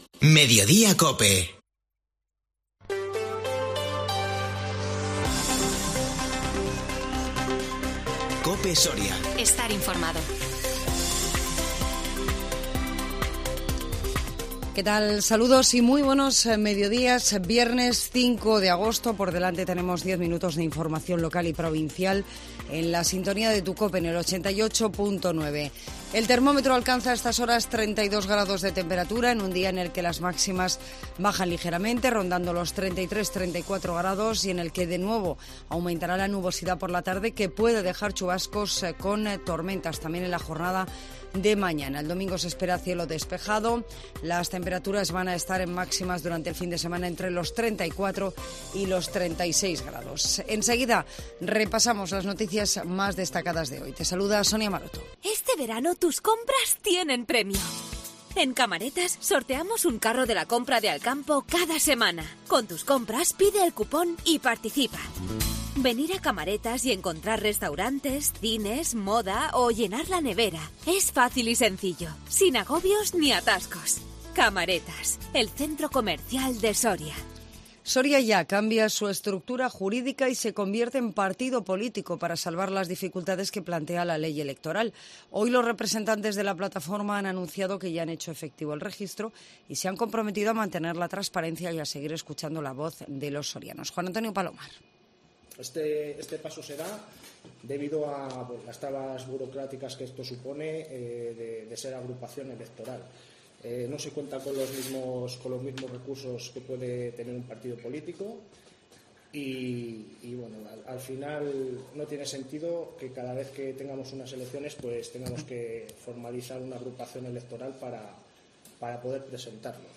INFORMATIVO MEDIODÍA COPE SORIA 5 AGOSTO 2022